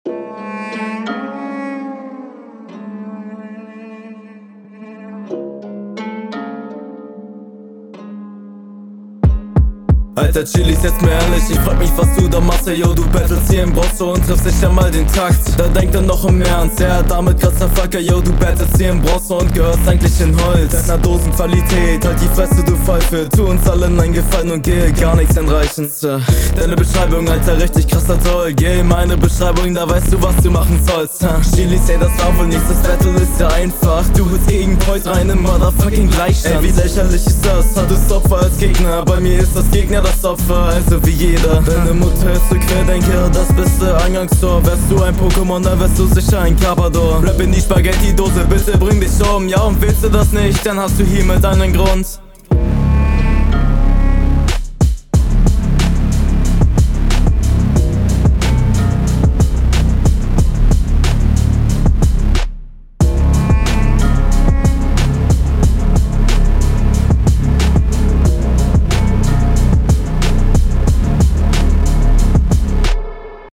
Ich feier deinen Flow und die Attitüde auf dem Beat echt sehr :) Die Holzliga-Line …
Nicer Flow stabile Technik aber …